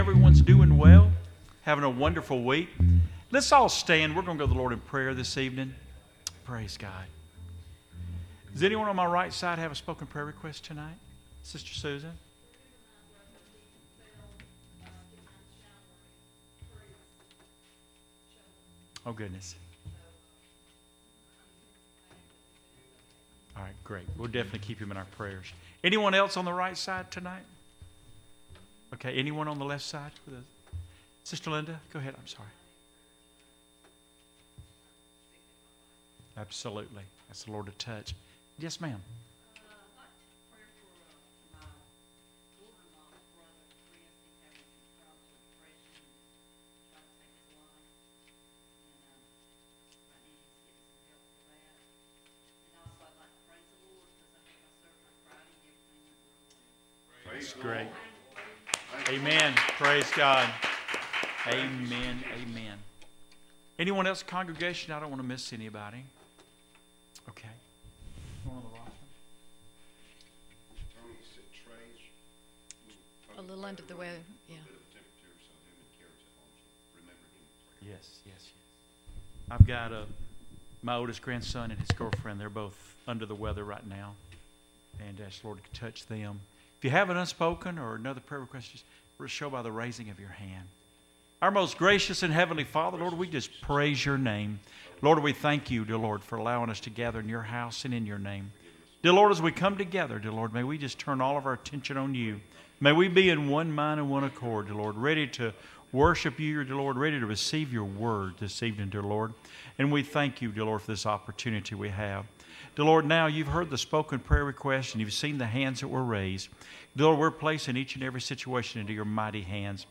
"2 Corinthians 5:14-17" Service Type: Wednesday Evening Services « Priority of Unity